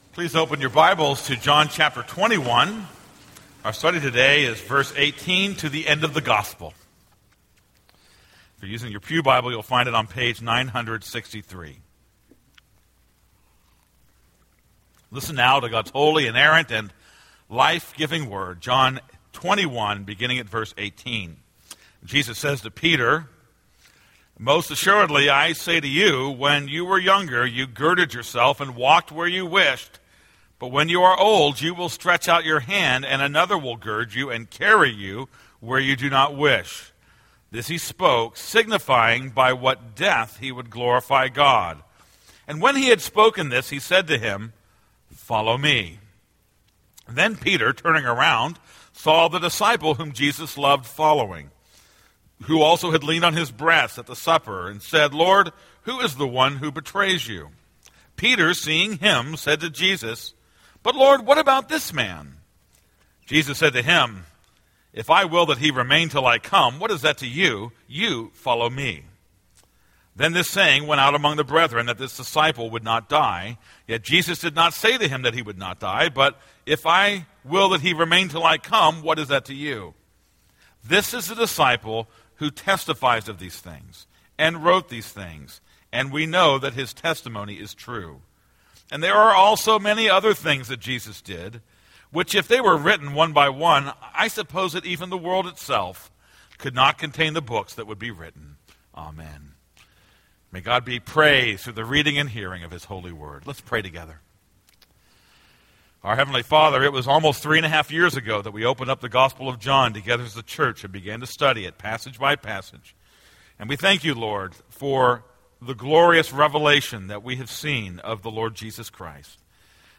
This is a sermon on John 21:18-25.